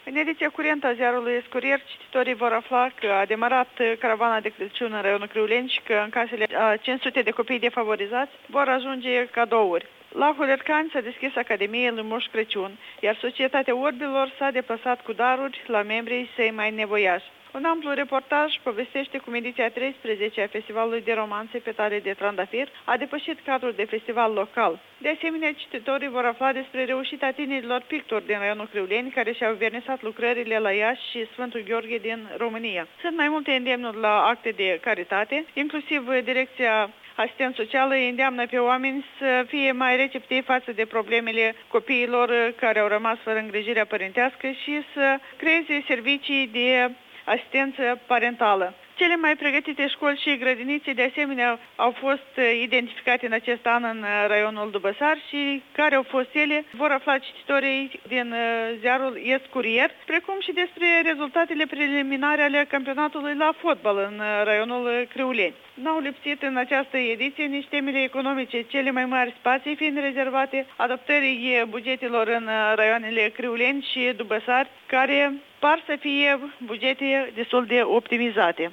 Reportaj de la Festivalul Romanțelor